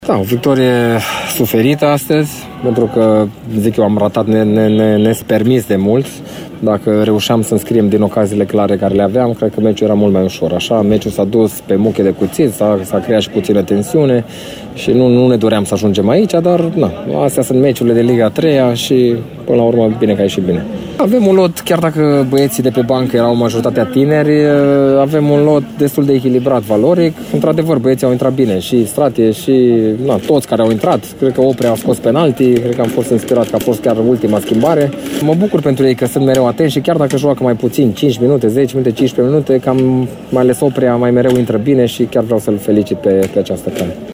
Paul Codrea, antrenorul Politehnicii Timișoara, a vorbit despre o victorie chinuită: